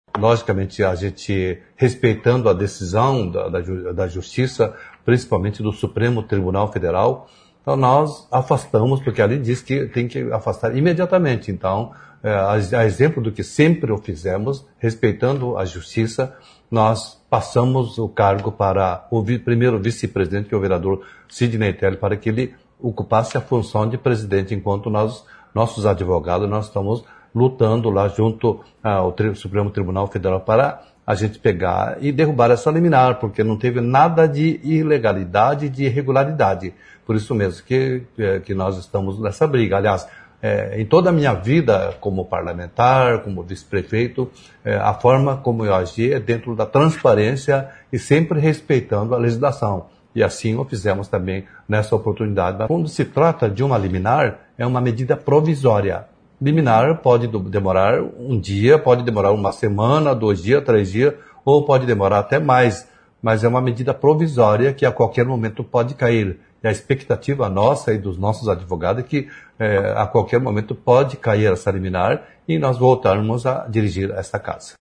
Em vídeo nas redes sociais, o vereador Mário Hossokawa fala sobre a decisão do Supremo Tribunal Federal que o afastou da presidência da Câmara. Ele disse que os advogados recorreram da decisão e reafirmou a legalidade da eleição da Mesa Diretora e dos atos legislativos que se seguiram.